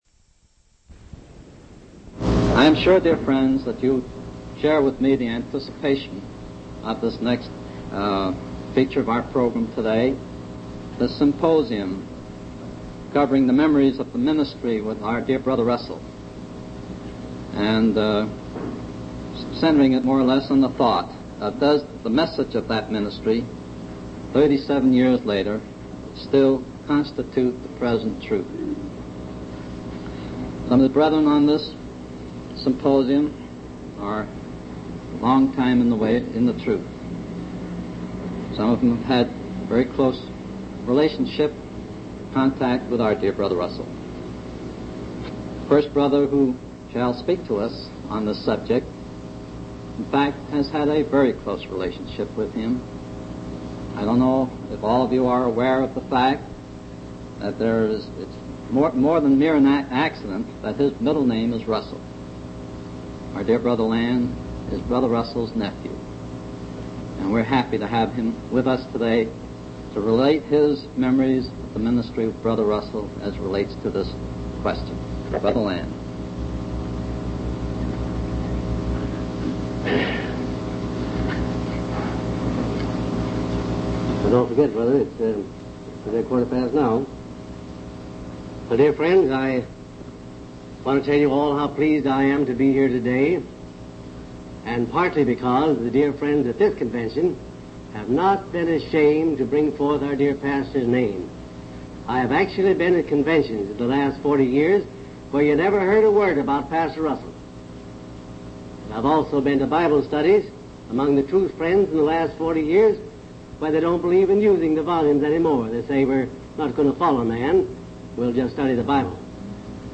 From Type: "Symposium"